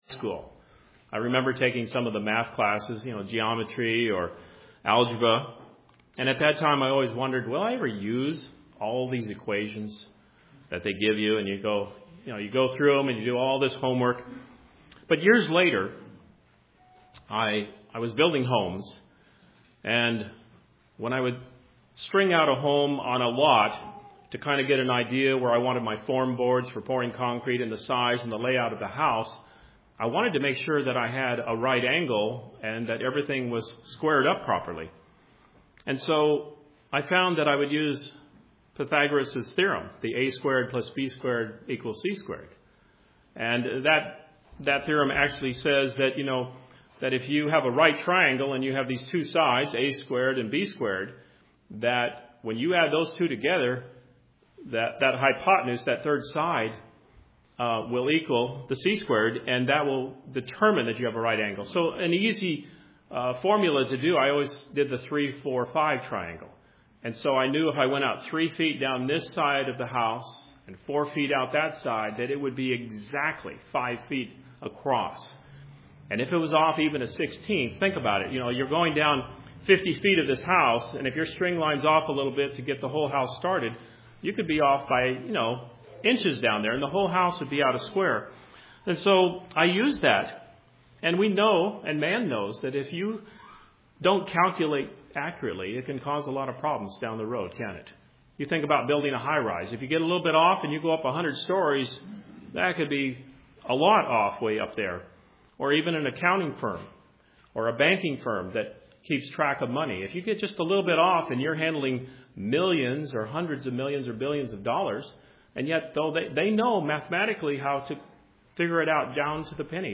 This sermon offers six points in how to better understand the bible.